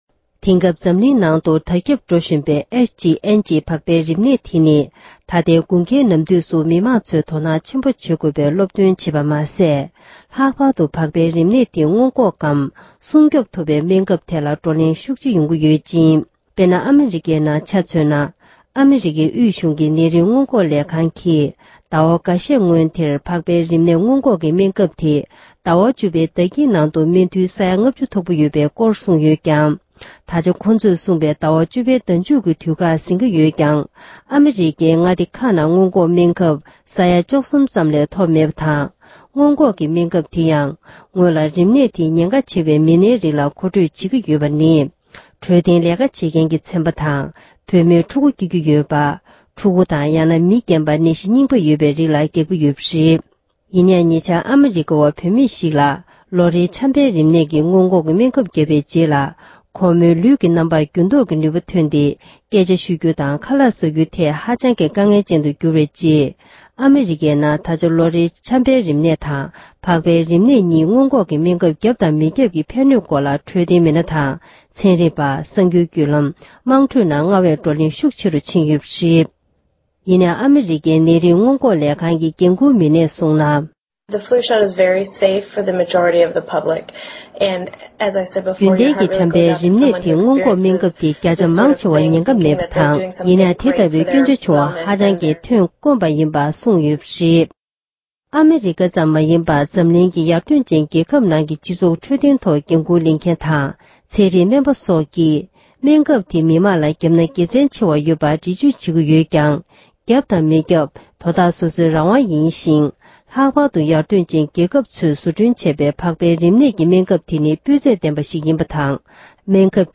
ཕག་པའི་རིམས་ནད་སྔོན་འགོག་གི་སྨན་ཁབ་དེའི་ཕན་གནོད་སྐོར་བགྲོ་གླེང༌།